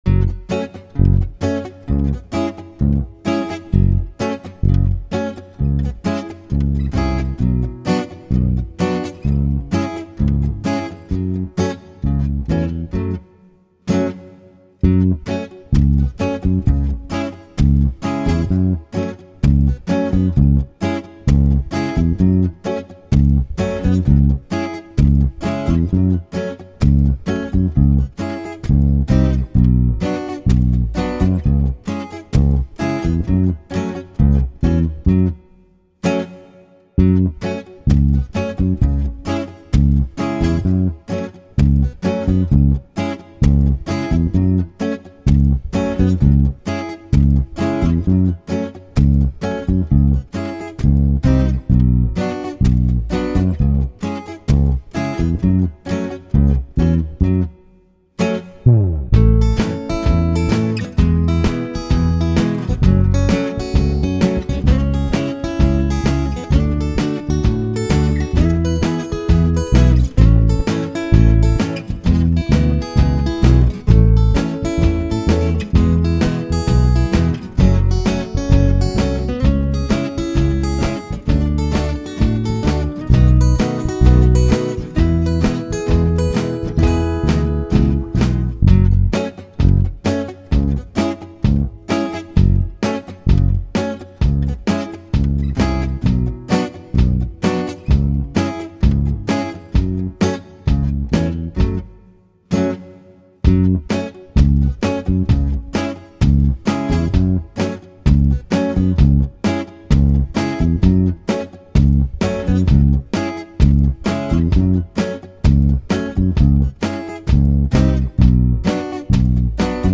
sans chant